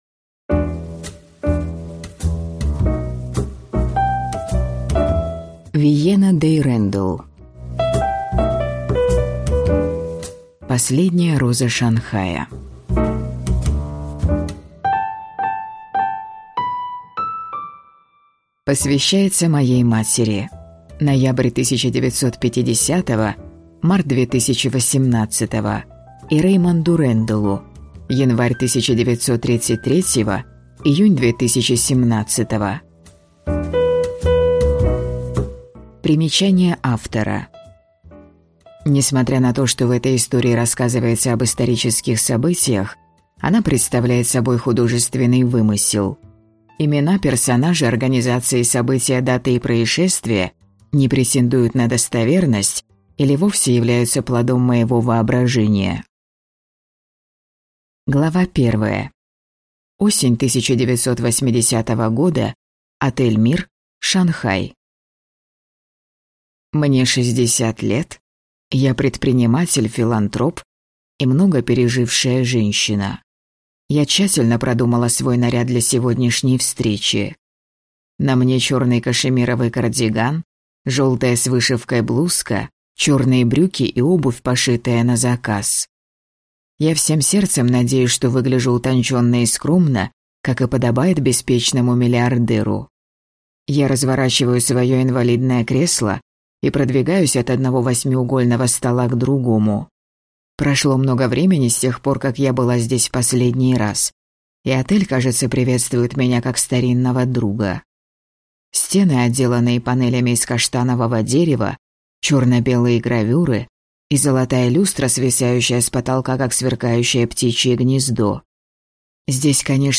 Аудиокнига Последняя роза Шанхая | Библиотека аудиокниг